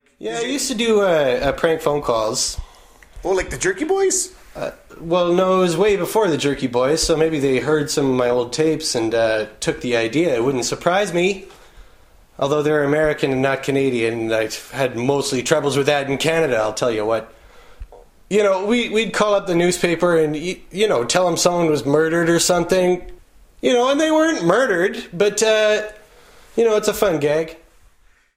prank-phone-calls.mp3